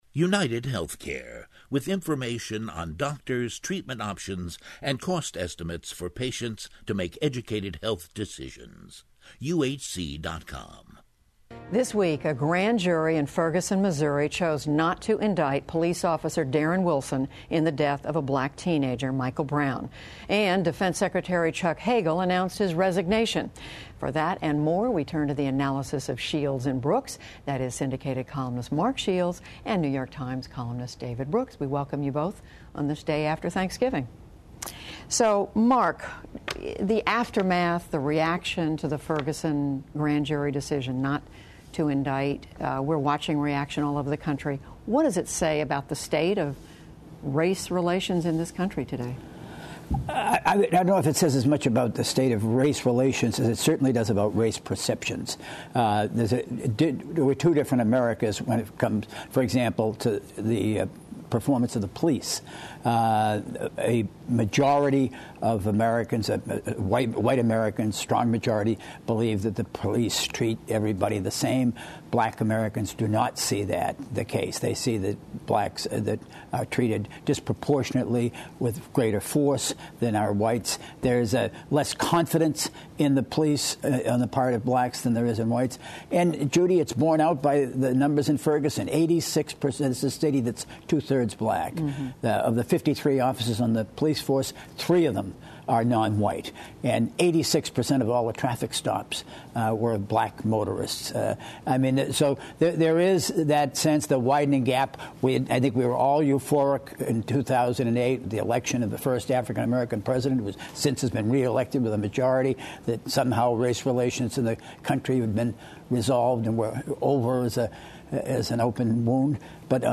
Syndicated columnist Mark Shields and New York Times columnist David Brooks join Judy Woodruff to discuss the week’s news, including the grand jury verdict on the death of Michael Brown in Ferguson, the resignation of Defense Secretary Chuck Hagel, plus what our commentators are thankful for this Thanksgiving.